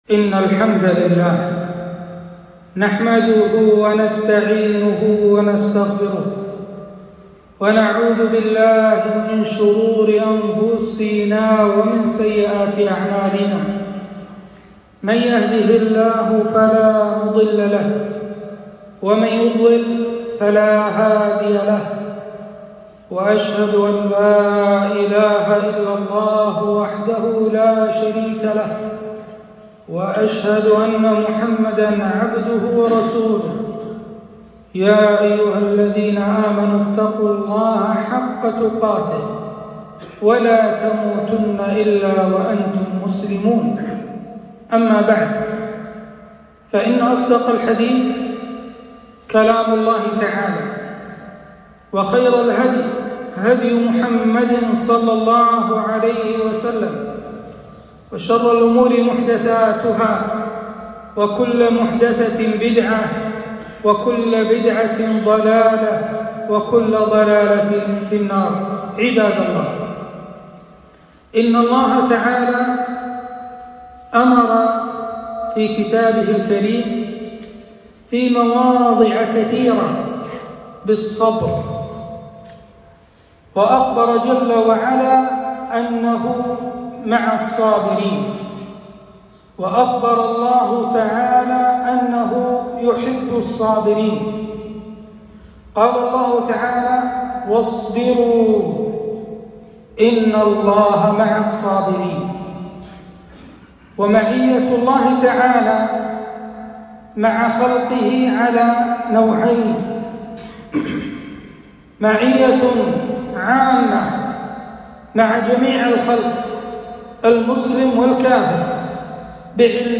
خطبة - الصبر على البلاء